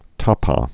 (täpä)